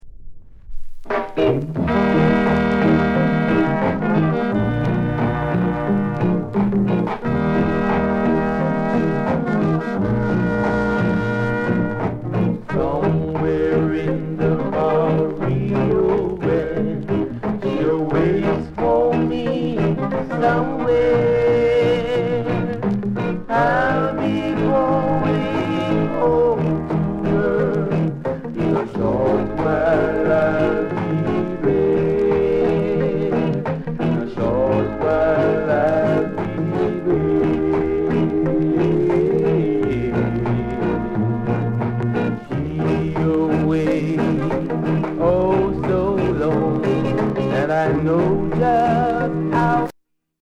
SOUND CONDITION A SIDE VG